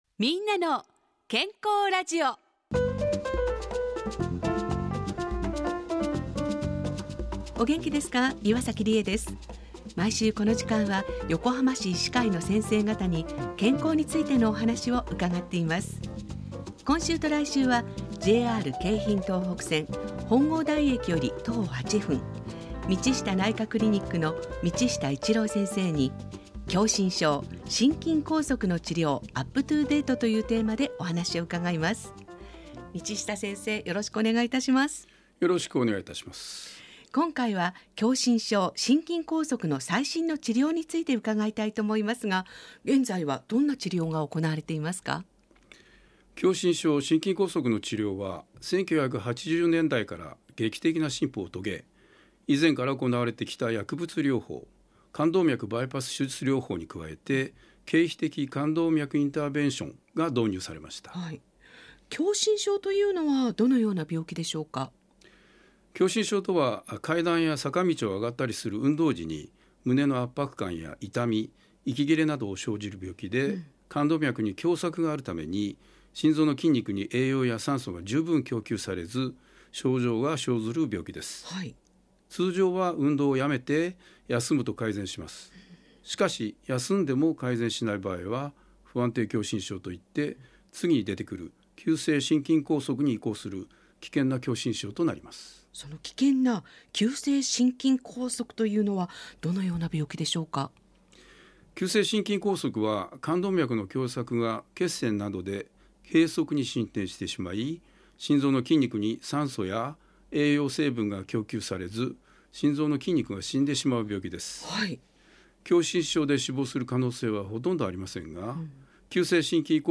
ラジオ番組 みんなの健康ラジオ